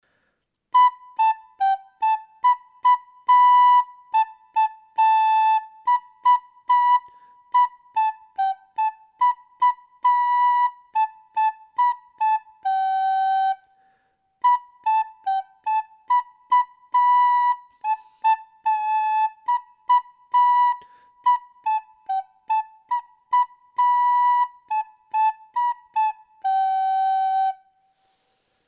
Agora practicaremos unha melodía coas notas SOL, LA e SI.